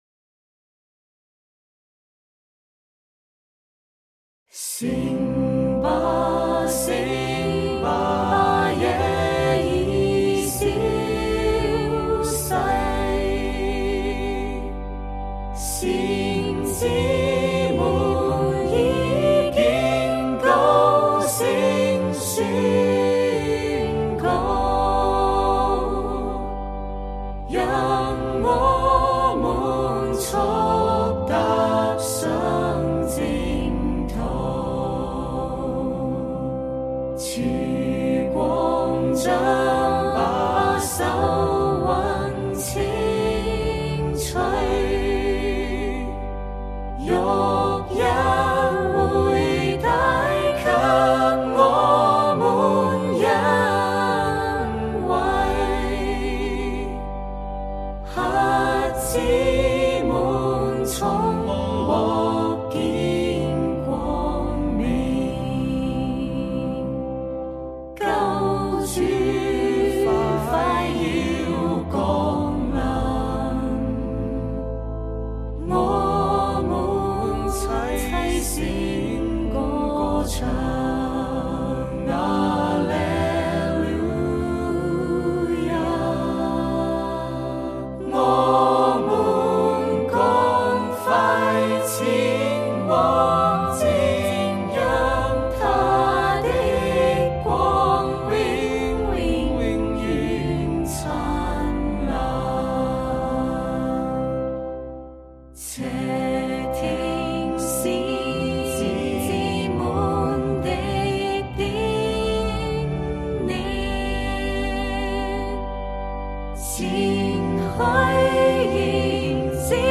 頌恩500 醒吧！ 醒吧 - SATB (C).mp3